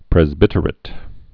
(prĕz-bĭtər-ĭt, -ə-rāt, prĕs-)